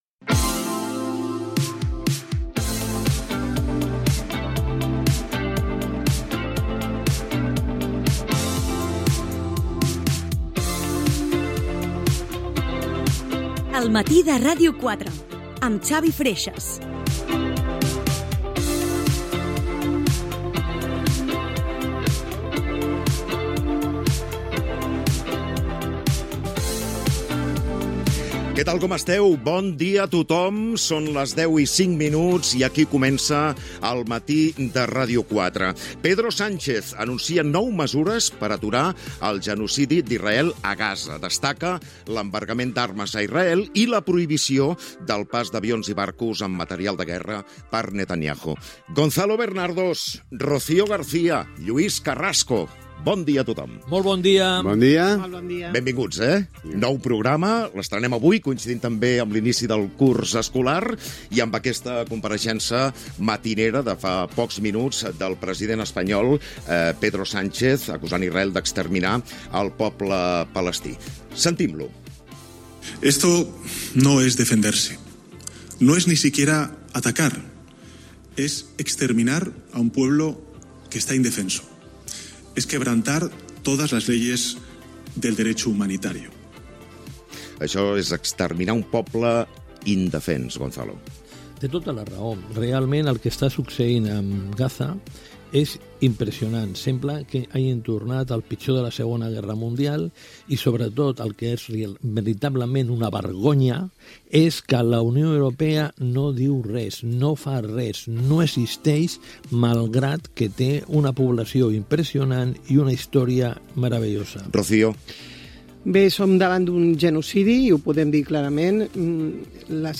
Primer programa en l'inici de la temporada 2025-2026.
Info-entreteniment